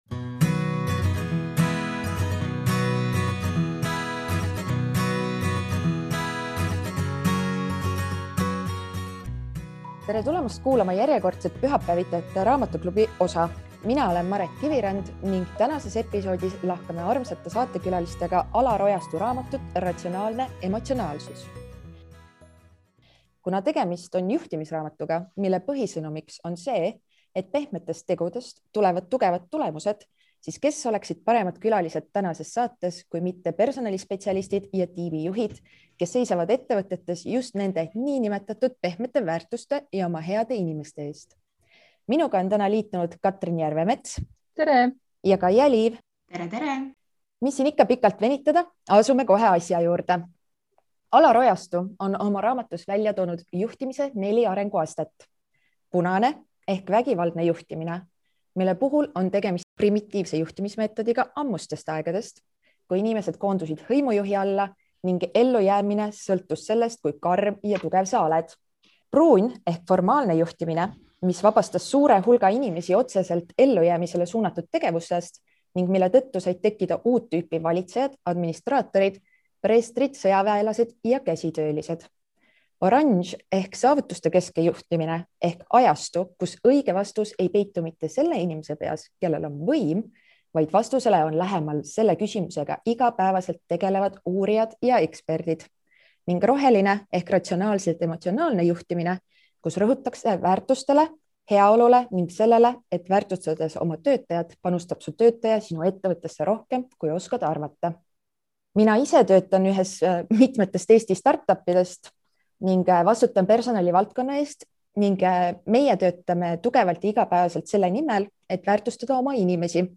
Koos EBS-i kursusekaaslastega arutasime kuidas mõtlemine ja emotsioonid mõjutavad meie otsuseid, juhtimist ja igapäevaseid valikuid.